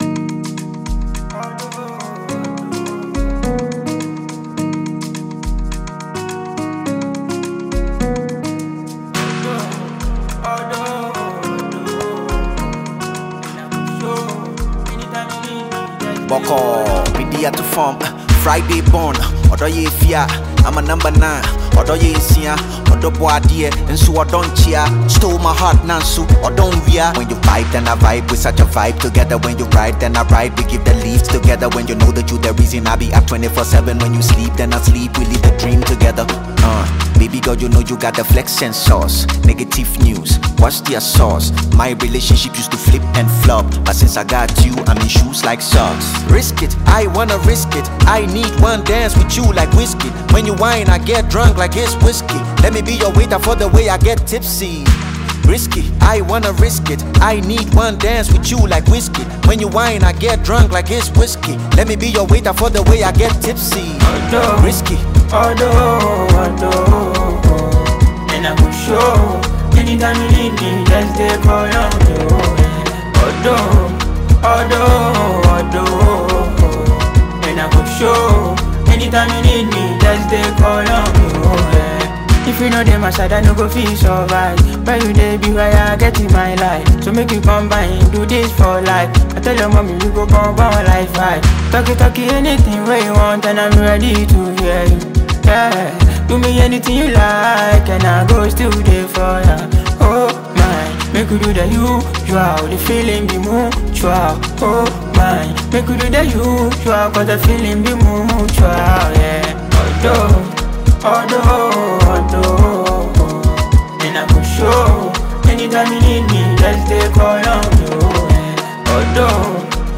emotionally rich track